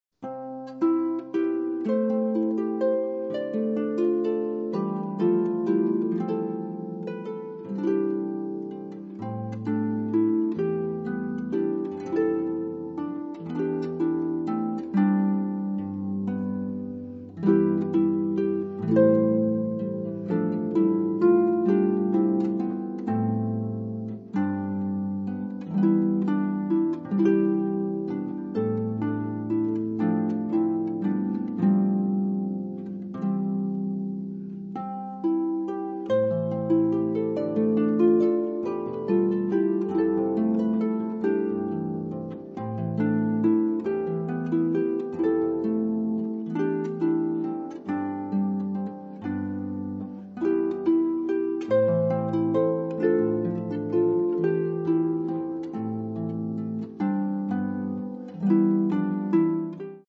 Genre: Hymns, Praise and Gospel